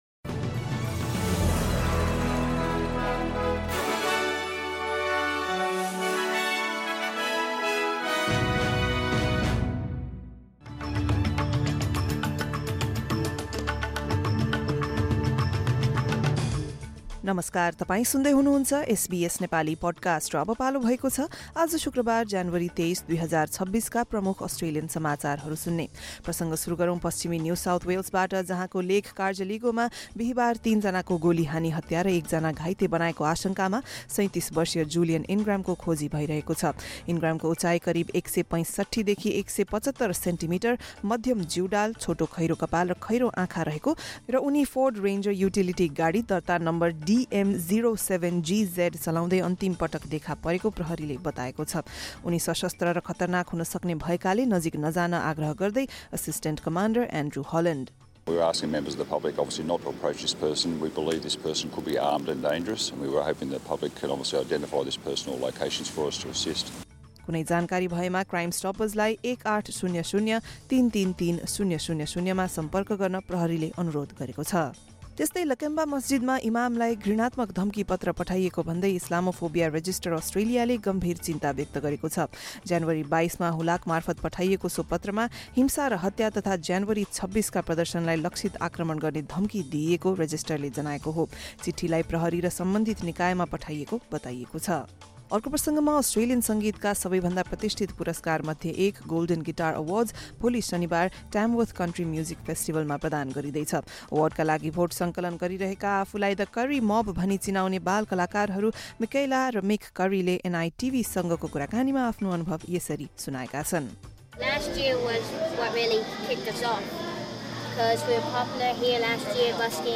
एसबीएस नेपाली प्रमुख अस्ट्रेलियन समाचार: शुक्रवार, २३ ज्यानुअरी २०२६